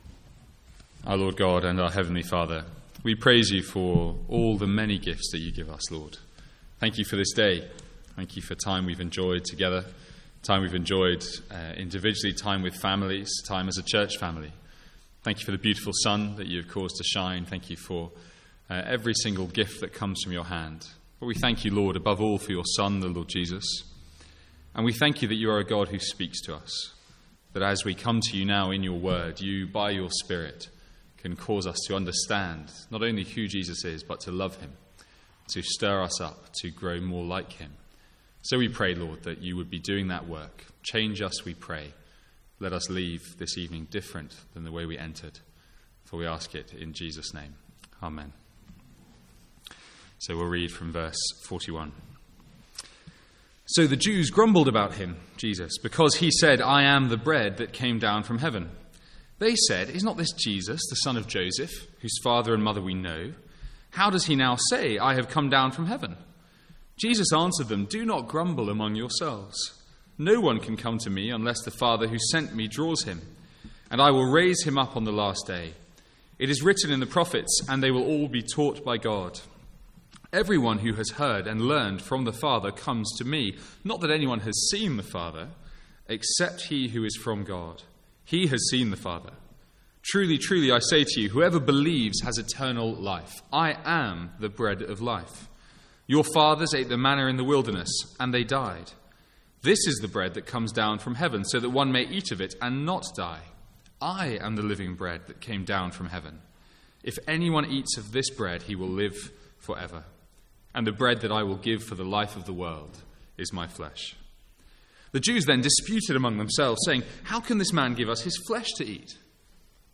One-off Sunday evening sermon.